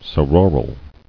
[so·ro·ral]